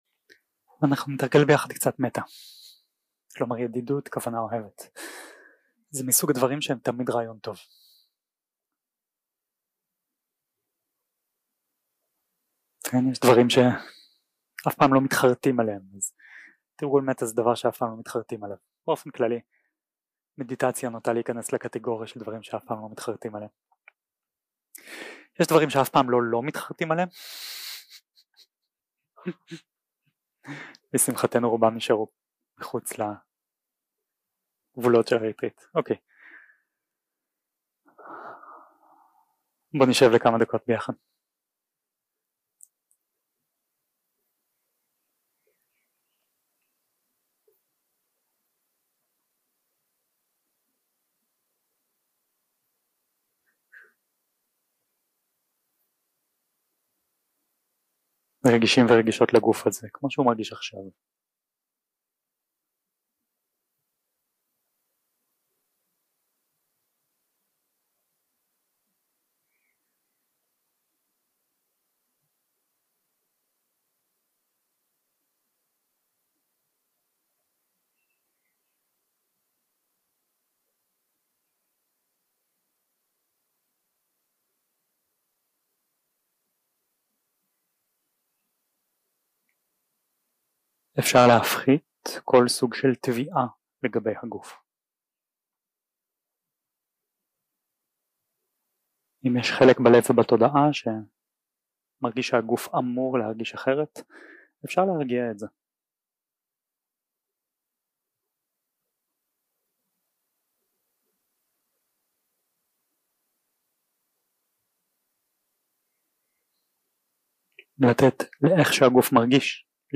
יום 2 – הקלטה 4 – ערב – שיחת דהארמה
Dharma type: Dharma Talks